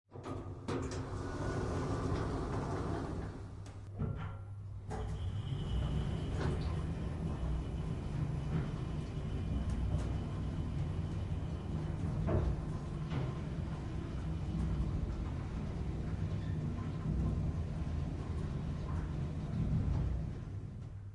ambient.ogg